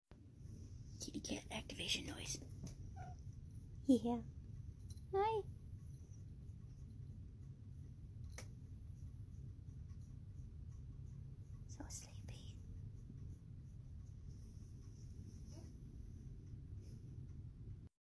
A chirp from a cat sound effects free download